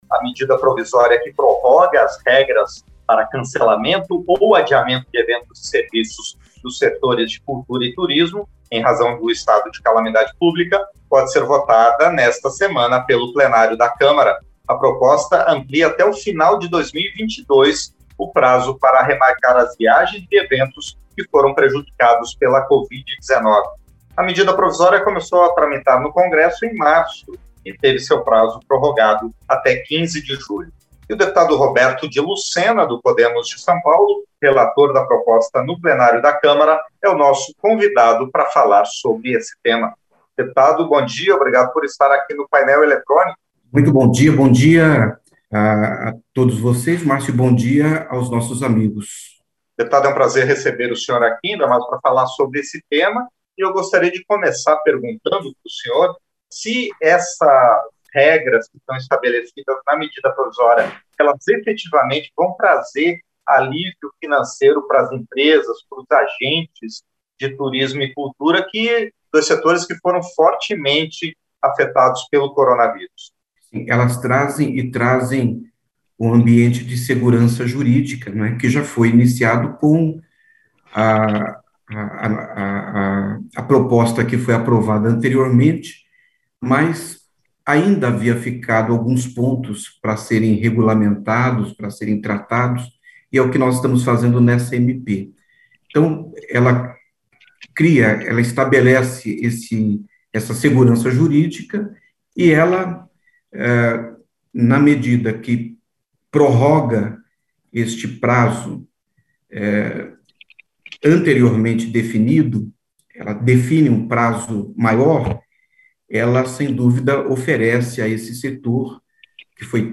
• Entrevista - Dep. Roberto de Lucena (PODE-SP)
Programa ao vivo com reportagens, entrevistas sobre temas relacionados à Câmara dos Deputados, e o que vai ser destaque durante a semana.